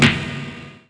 klack4.mp3